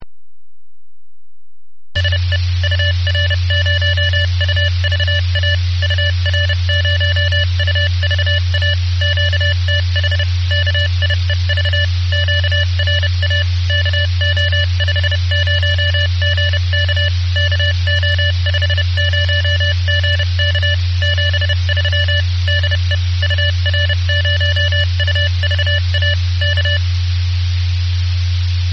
Морзянка в Киеве на 145.675 Мгц